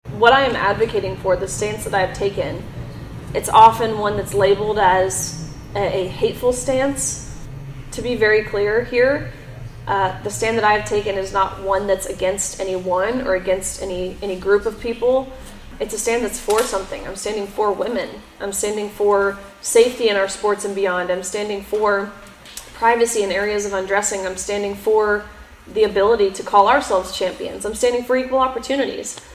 Riley Gaines made her way to Marshall to speak at the 2025 Annual Saline County Lincoln Day Dinner, sharing her story to the more than 500 residents in attendance in the Martin Community Center.